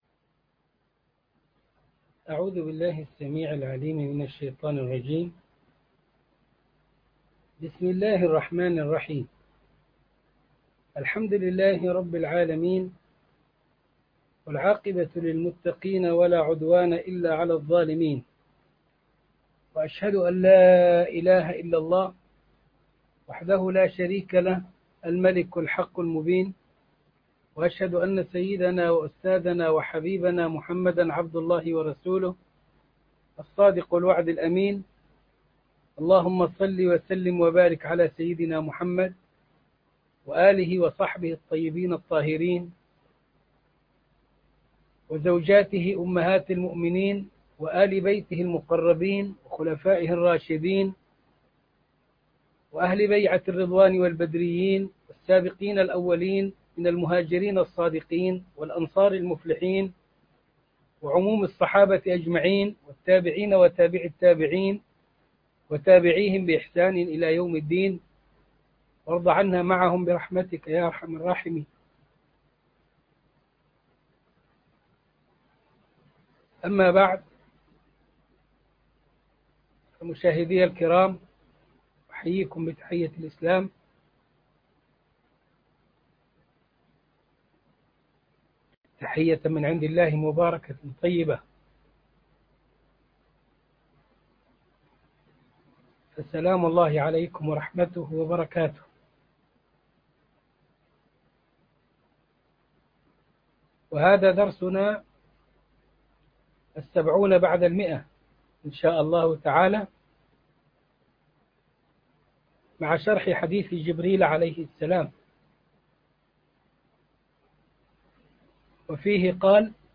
عنوان المادة الدرس ( 170 ) ( شرح حديث جبريل عن الاسلام والايمان والاحسان) تاريخ التحميل الأحد 28 فبراير 2021 مـ حجم المادة 35.46 ميجا بايت عدد الزيارات 199 زيارة عدد مرات الحفظ 97 مرة إستماع المادة حفظ المادة اضف تعليقك أرسل لصديق